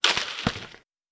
Foley Sports / Basketball / Generic Net Hit Intense.wav
Generic Net Hit Intense.wav